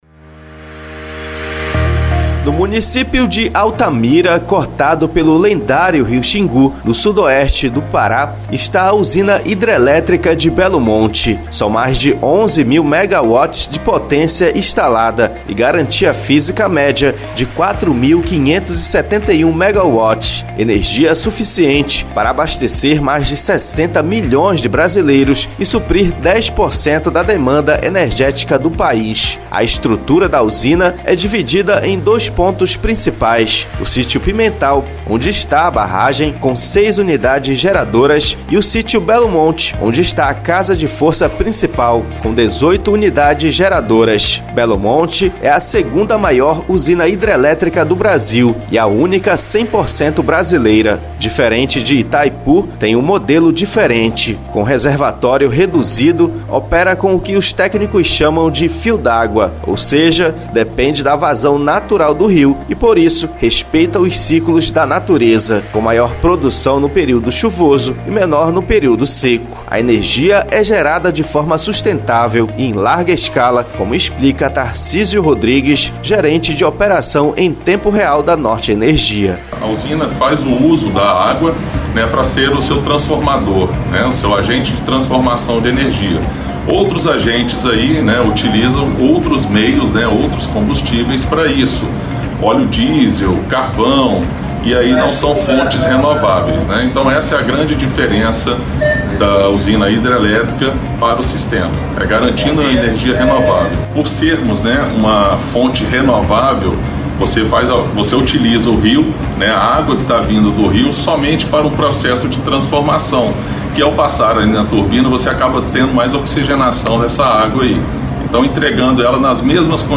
Na primeira reportagem especial da CBN Amazônia Belém, sobre a usina Hidrelétrica de Belo Monte, em Altamira, sudoeste do Pará, a energia sustentável gerada pela usina e o cuidado com o meio ambiente